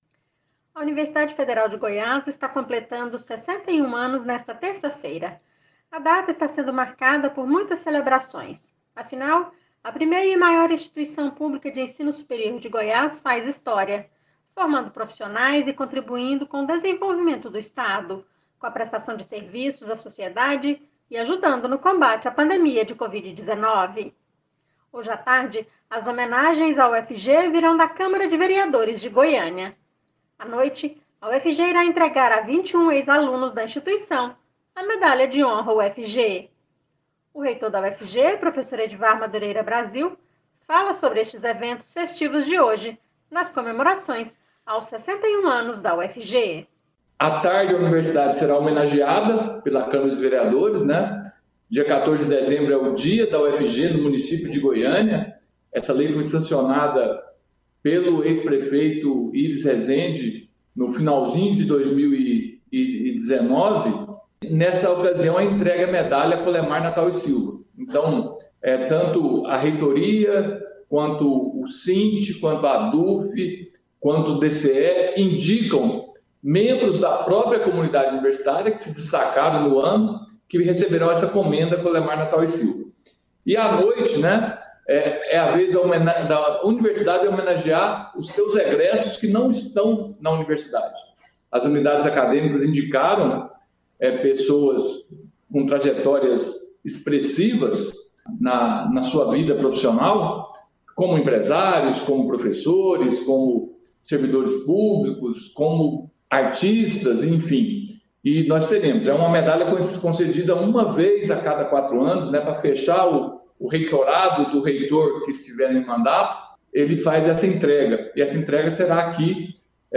Confira entrevista do reitor Edward Madureira para a Rádio Universitária